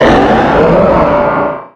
Cri de Méga-Camérupt dans Pokémon Rubis Oméga et Saphir Alpha.
Cri_0323_Méga_ROSA.ogg